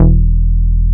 R MOOG E2MF.wav